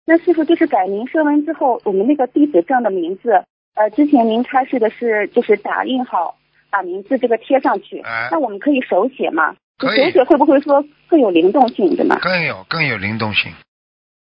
但因对话交流带有语气，文字整理不可能完全还原情境。